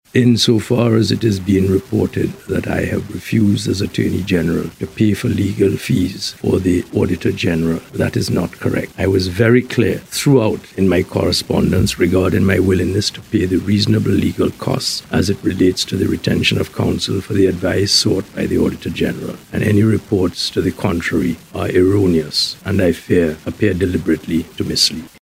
During today’s post-cabinet briefing, Mr Armour maintained that at no time did he refuse to pay.